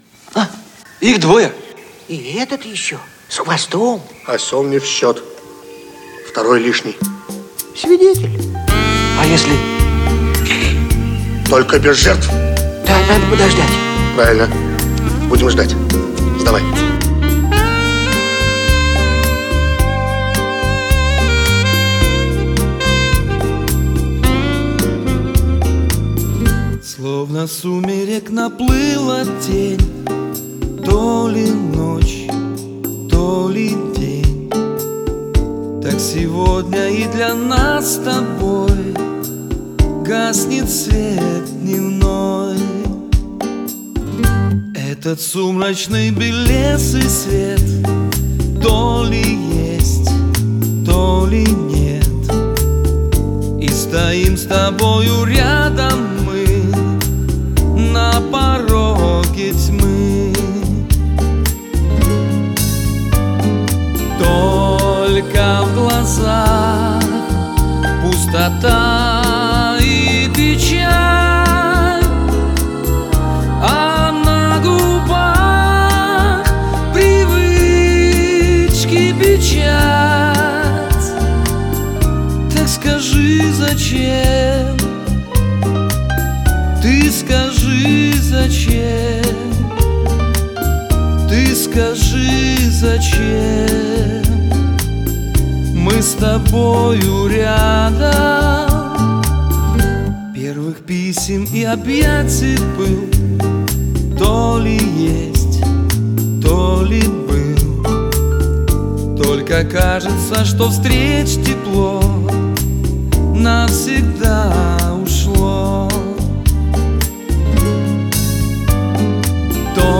вокал, гитара
вокал
альт-саксофон, клавишные
ударные, перкуссия